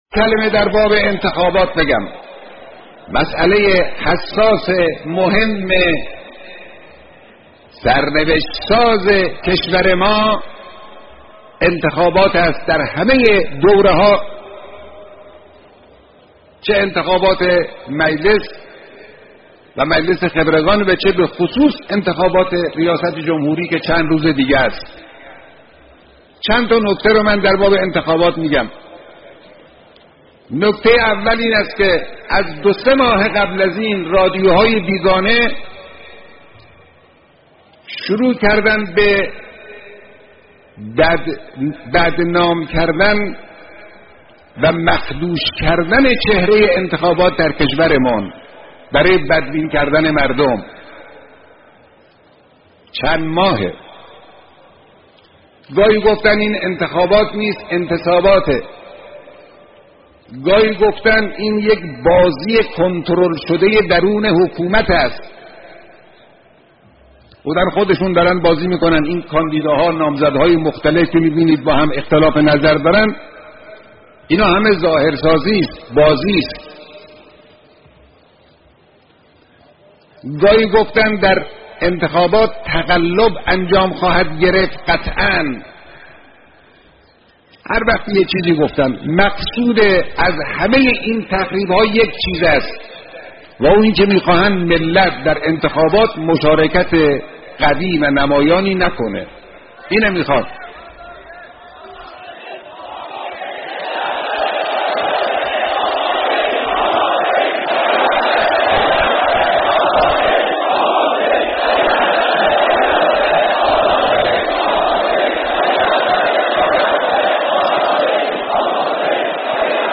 گزیده‌ی بیانات در مورد انتخابات در حرم امام خمینی (ره)